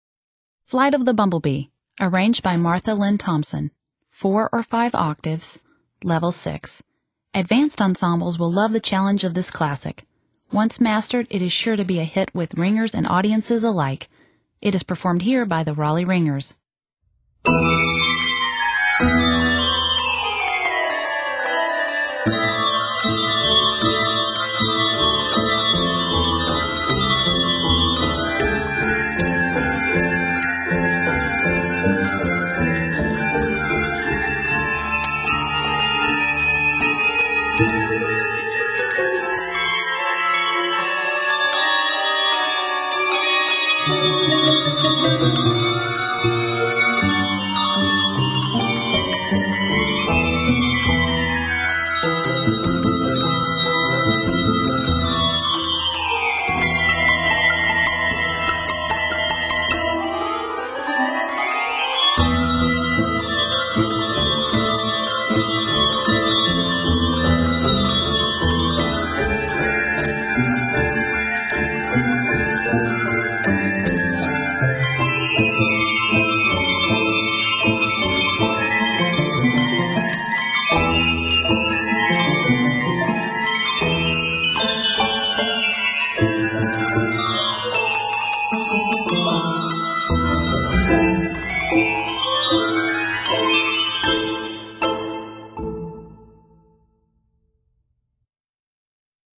Your advanced handbell choir